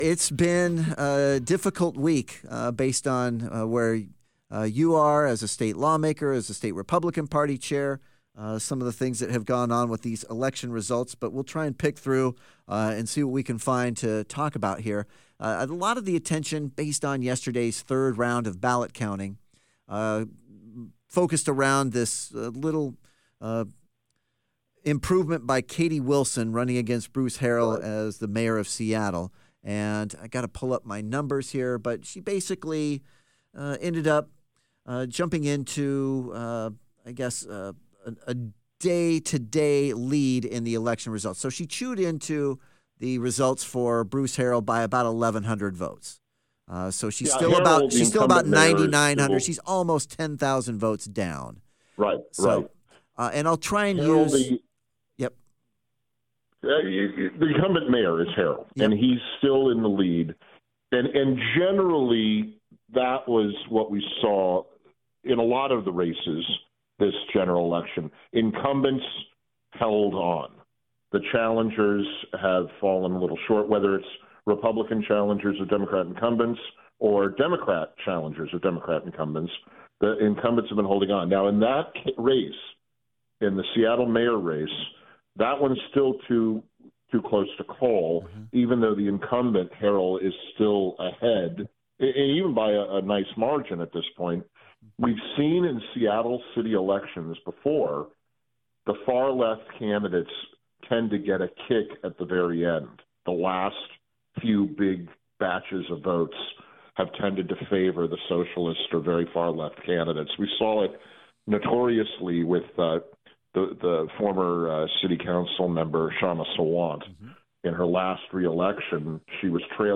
Washington State Republican Party Chair and State Rep. Jim Walsh joined The Morning Ride to unpack the latest election results and ongoing ballot counting in King County. Walsh said many incumbents held their seats, but noted the Seattle mayor’s race between Bruce Harrell and Katie Wilson remains “too close to call,” with concern that late-counted ballots could again tilt left, as seen in past Seattle elections.